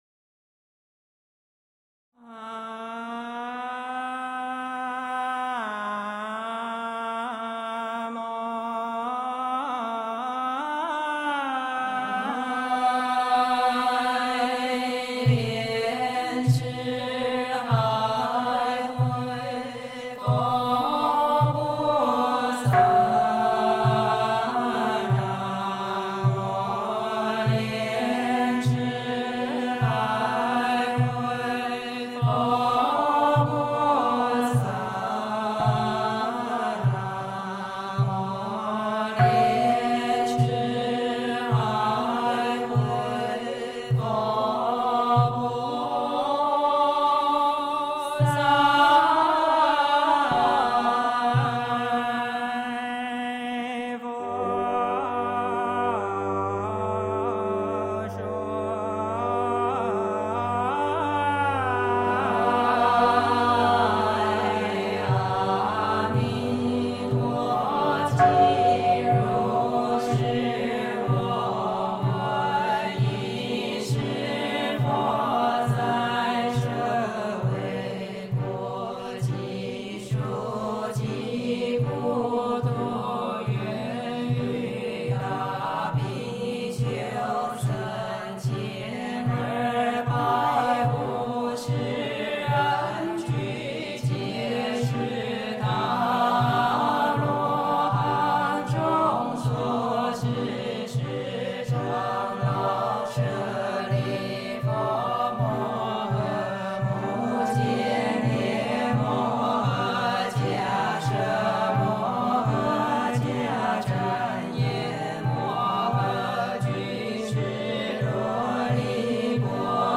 阿弥陀经 - 诵经 - 云佛论坛